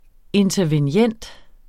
Udtale [ entʌvenˈjεnˀd ]